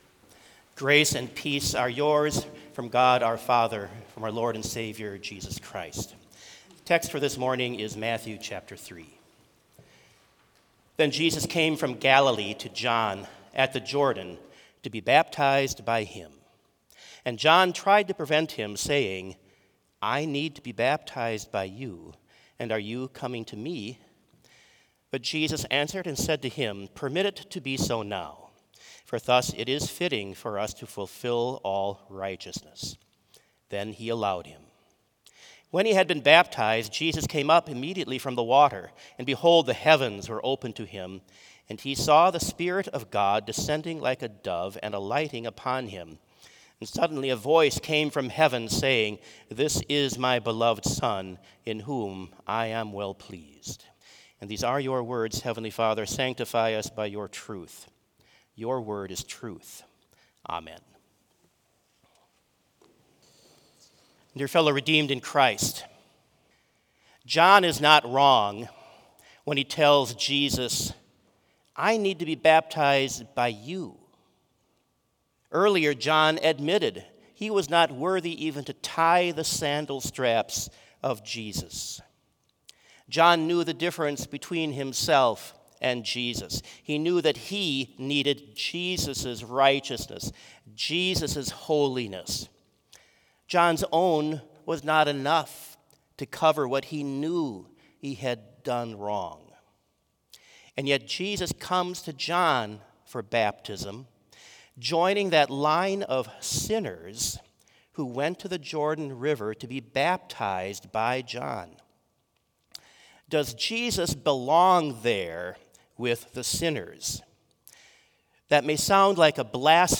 Complete Service
• Prelude
• Devotion
This Chapel Service was held in Trinity Chapel at Bethany Lutheran College on Wednesday, March 12, 2025, at 10 a.m. Page and hymn numbers are from the Evangelical Lutheran Hymnary.